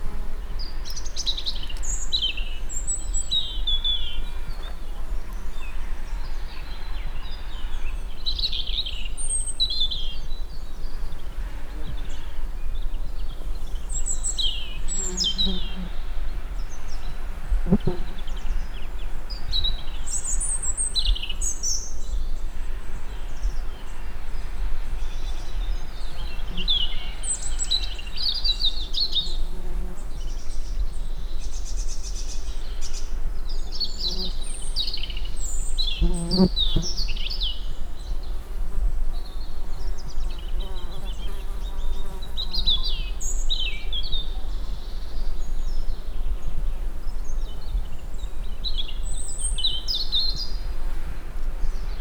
rovarok_professzionalis2016
jobbmajdbalmikrofonnalis_potamia00.52.WAV